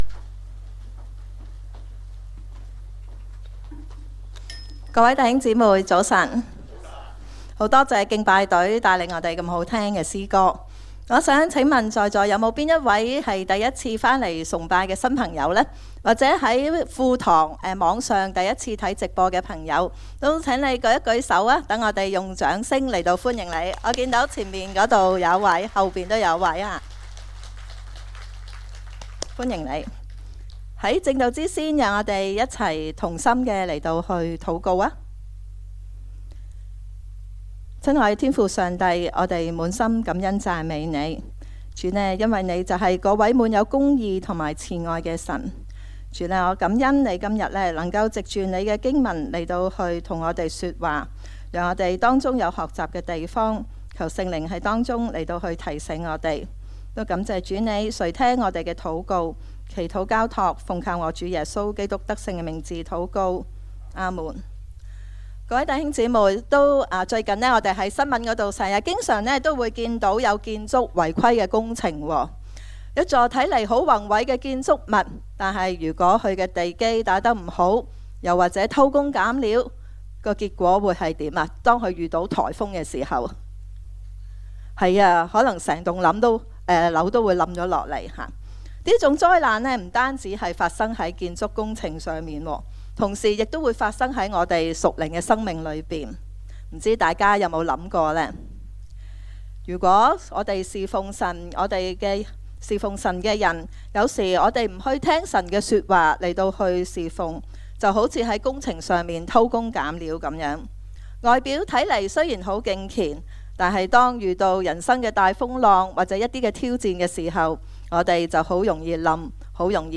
證道集